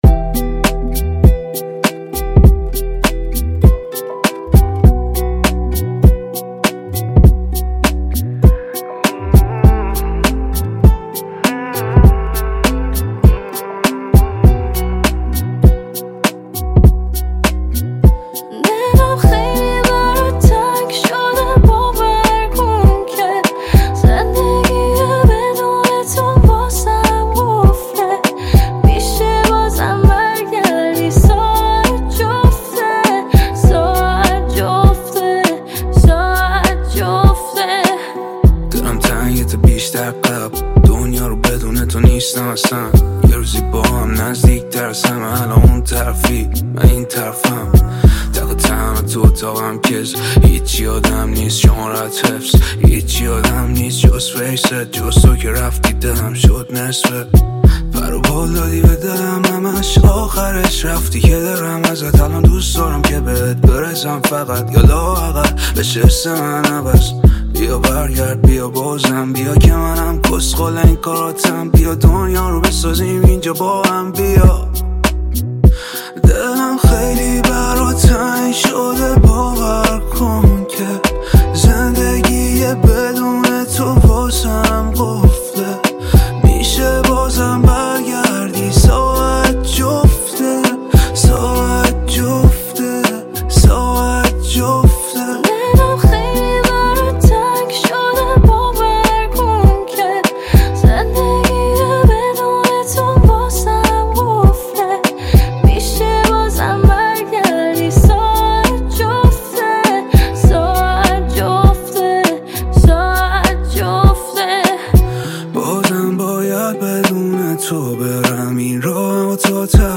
پاپ شاد عاشقانه